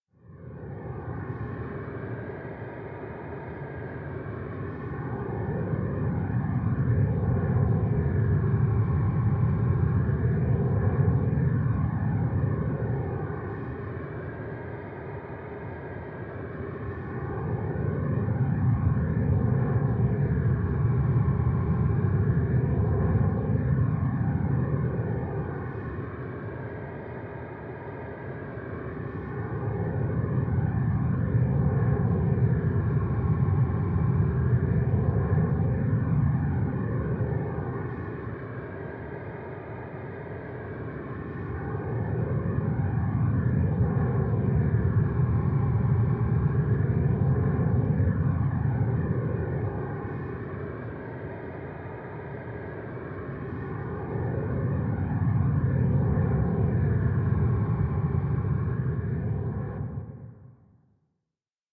Звук зловония